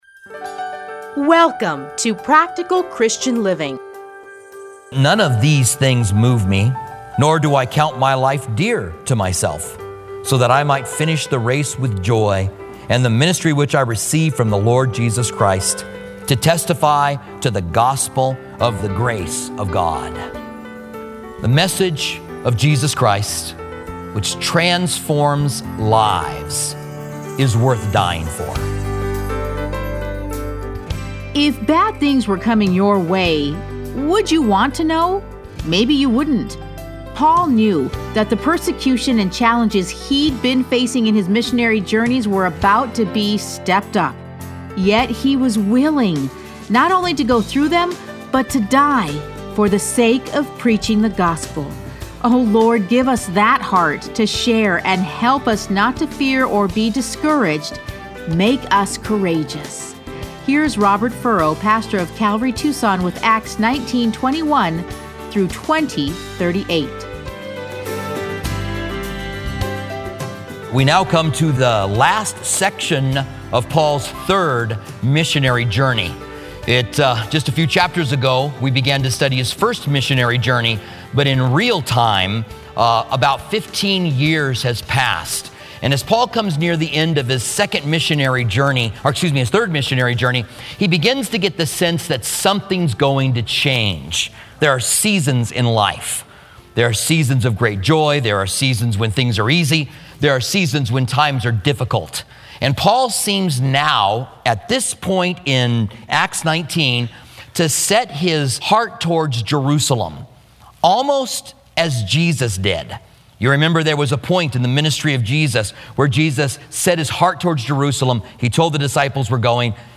Listen to a teaching from Acts 19:21 to 20:38.